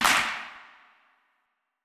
TC2 Clap8.wav